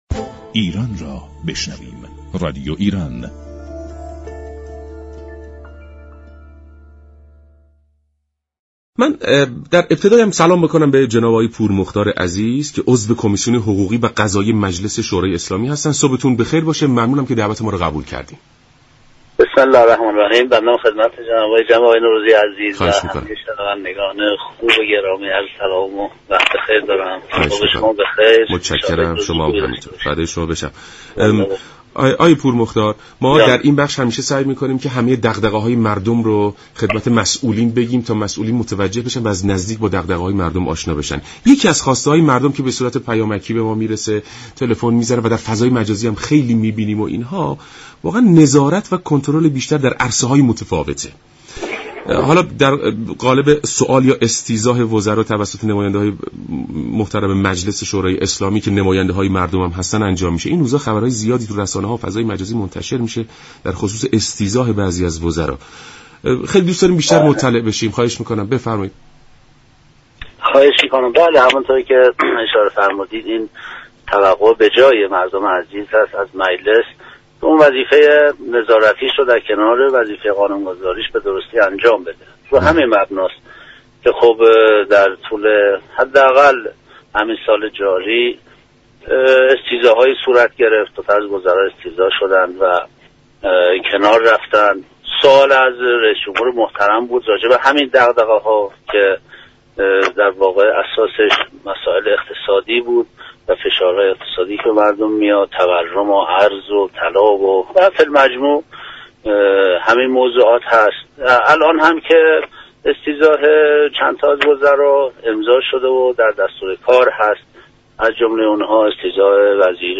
محمدعلی پورمختار عضو كمیسیون حقوقی و قضایی مجلس در گفت و گو با برنامه «سلام صبح بخیر» رادیو ایران گفت .